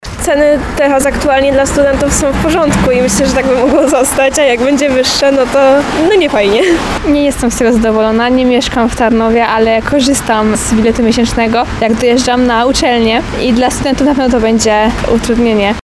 Nie mieszkam w Tarnowie, ale korzystam z biletu miesięcznego, jak dojeżdżam na uczelnię i dla studentów takich jak ja, to na pewno będzie utrudnienie – mówi inna studentka.